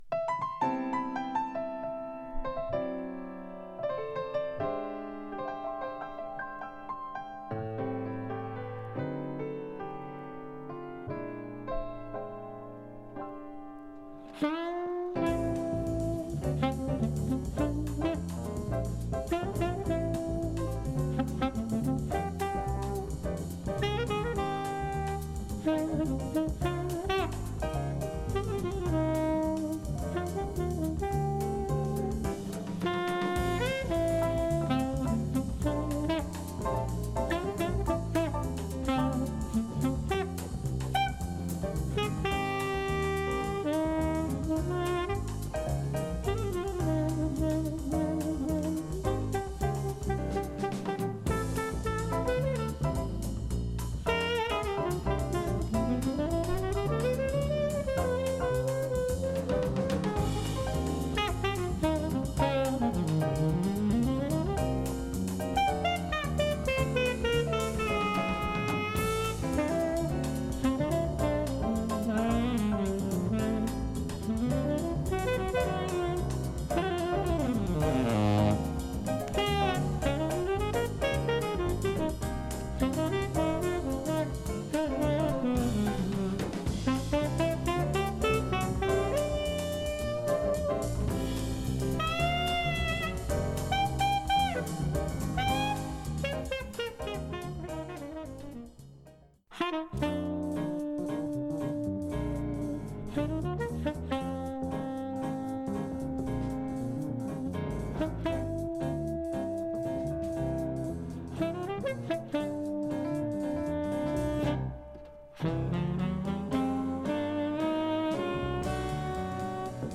discription:Stereo